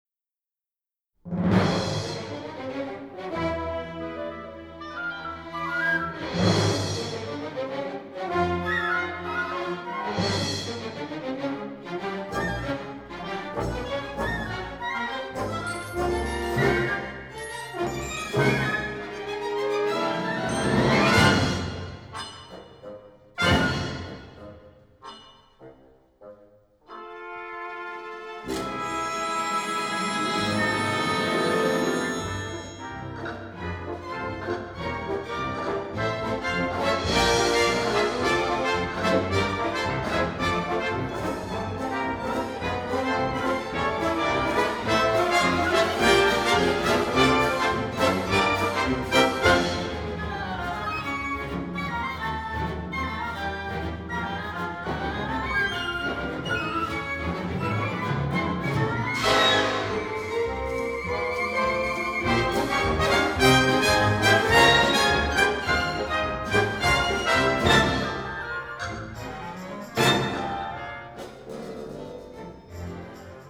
古典音樂
經他的單簧管所演奏出的音色格外柔美潤滑、富有截然不同的氣派